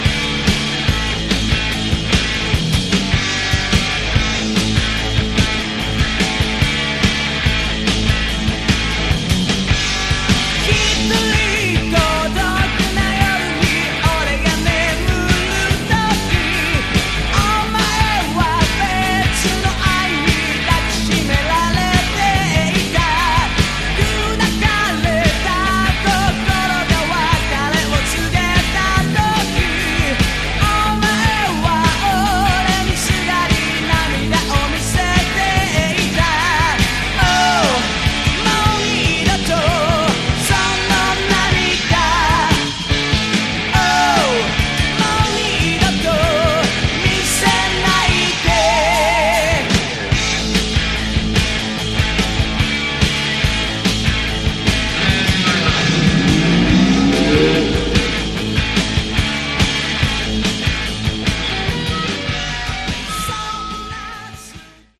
Category: Hard Rock
bass
guitar
vocals
drums